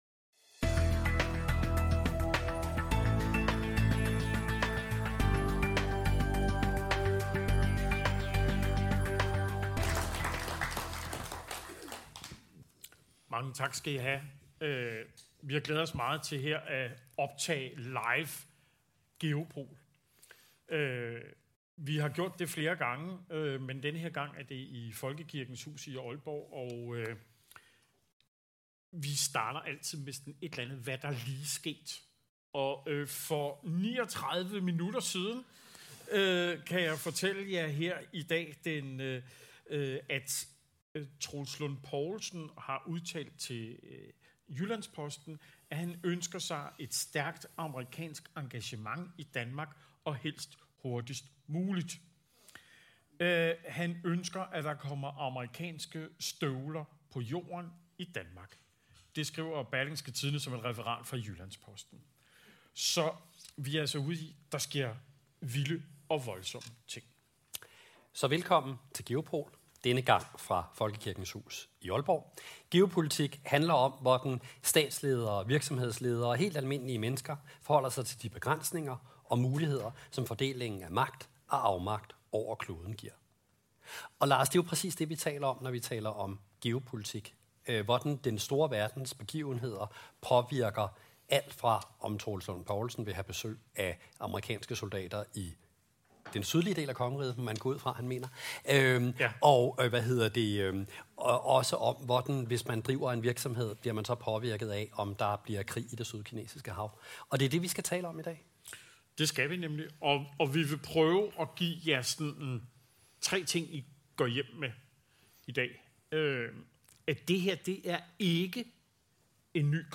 live fra Folkekirkens Hus i Aalborg (optaget 26. februar)